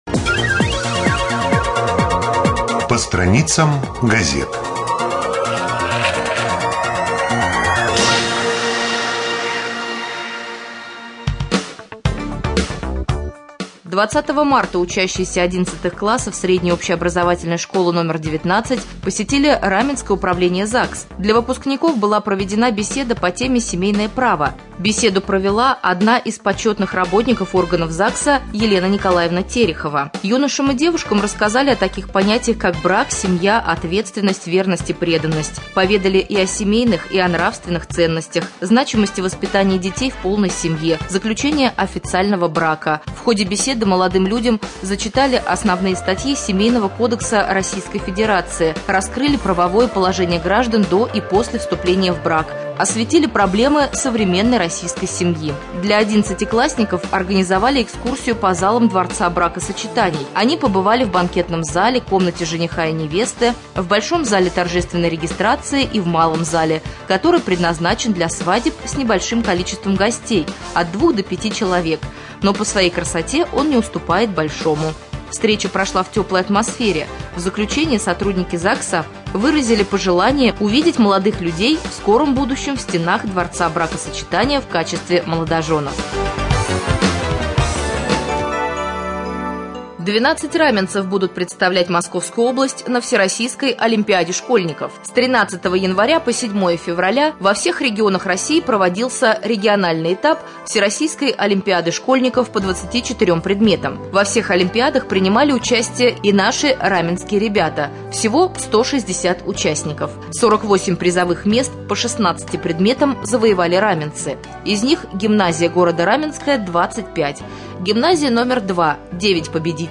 02.04.2014г. в эфире раменского радио - РамМедиа - Раменский муниципальный округ - Раменское
1.Рубрика «По страницам прессы».